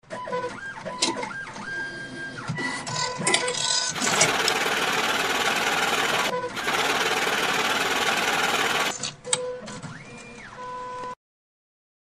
atmmoneyinout.mp3